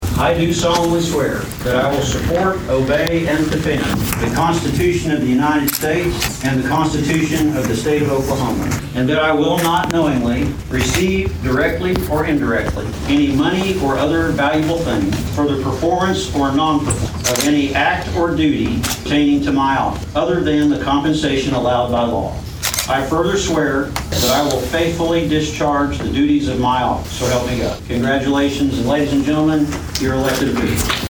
Here is District Court Judge Stuart Tate
reading the officials their oath of office.